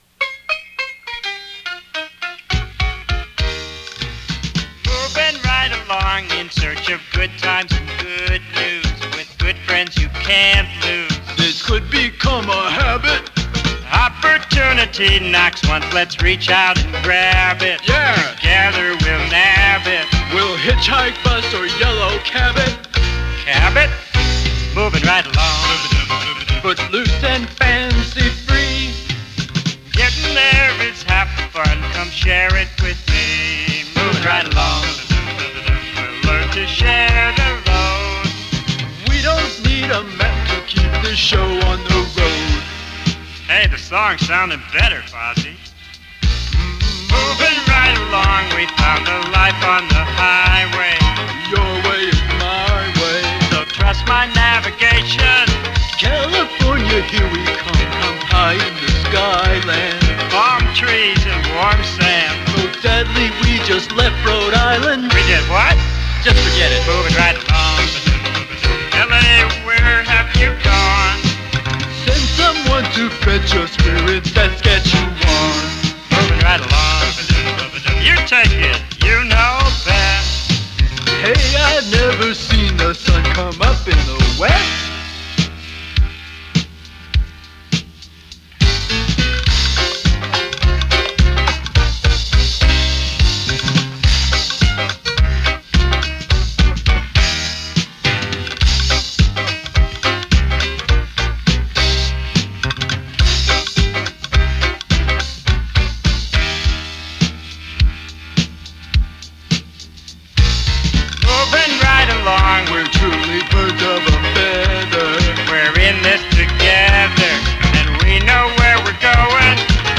disco renditions